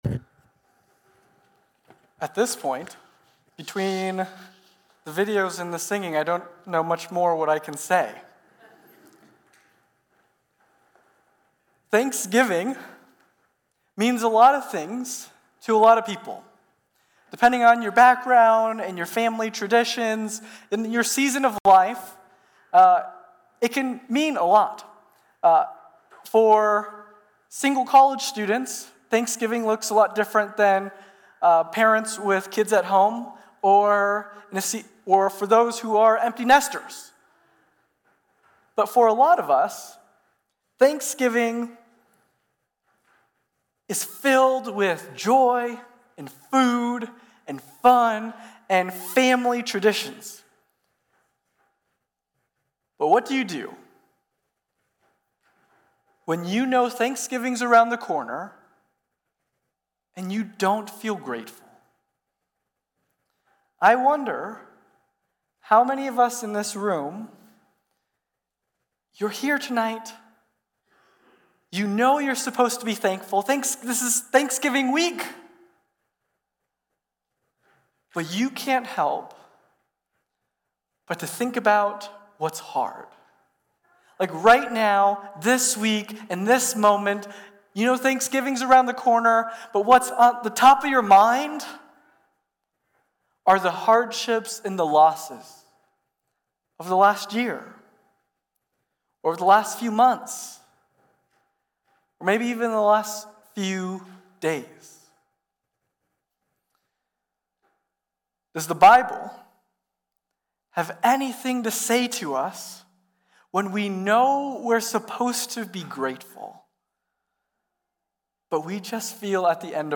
Passage: Psalm 138 Service Type: Worship Gathering Topics